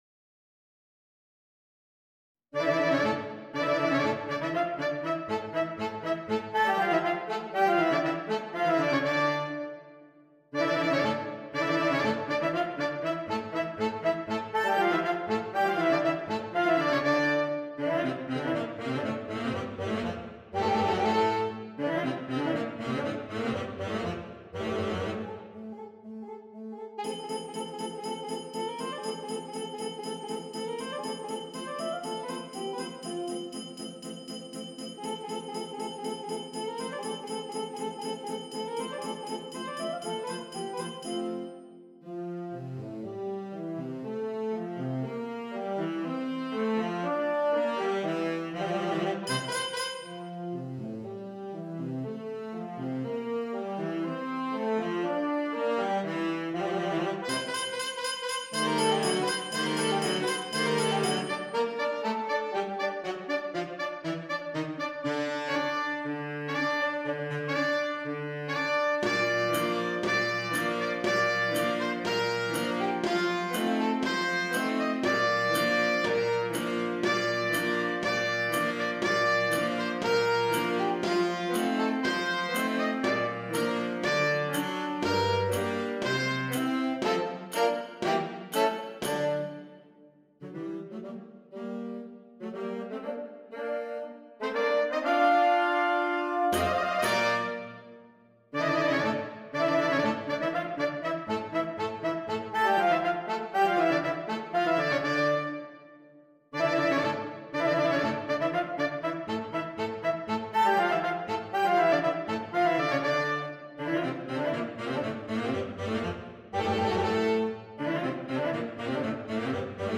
Saxophone Quartet (SATB or AATB)
This is a flashy piece and everyone gets a good part.